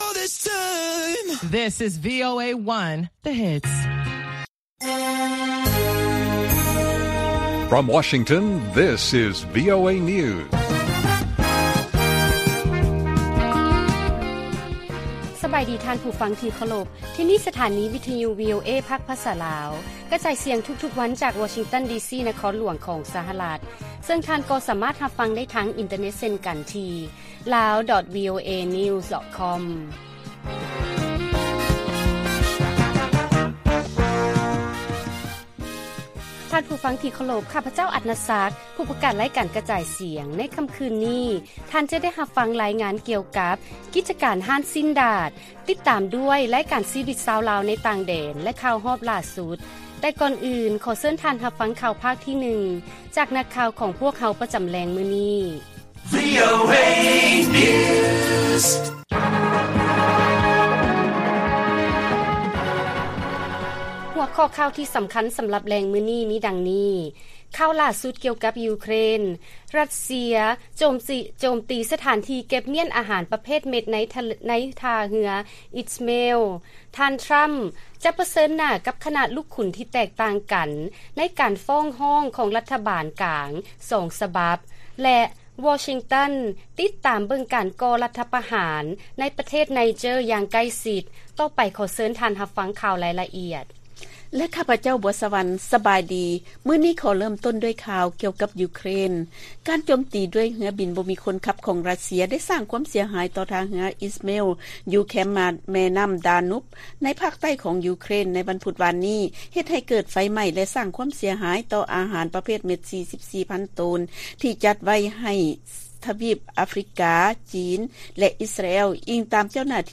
ລາຍການກະຈາຍສຽງຂອງວີໂອເອ ລາວ: ຂ່າວຫຼ້າສຸດກ່ຽວກັບຢູເຄຣນ: ຣັດເຊຍໂຈມຕີ ສະຖານທີ່ເກັບມ້ຽນ ອາຫານປະເພດເມັດໃນທ່າເຮືອອິສເມລ